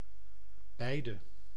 Ääntäminen
IPA: /ˈbɛi̯.də/